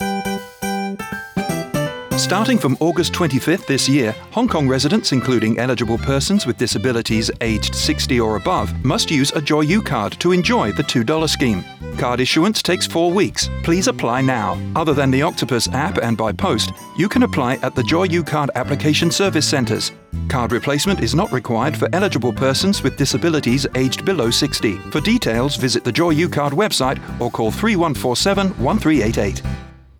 Radio Announcement